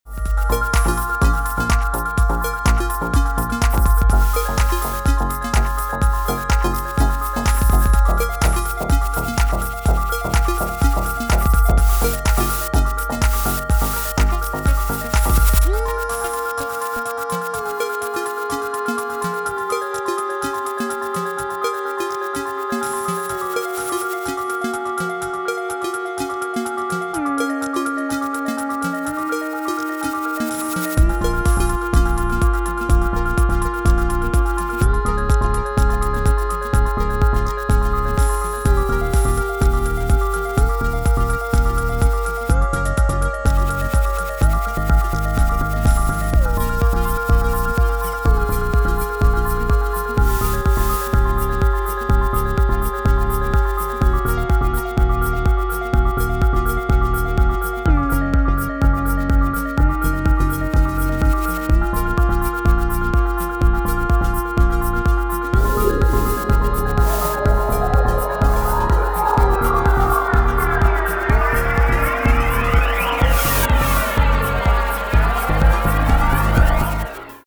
Genres Tech House